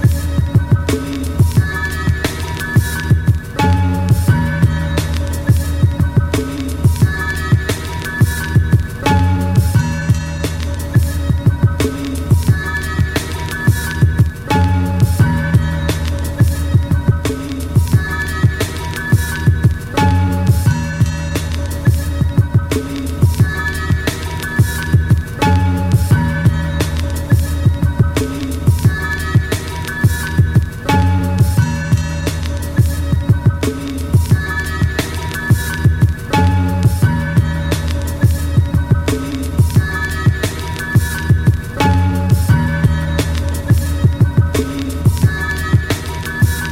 Hip Hop Funk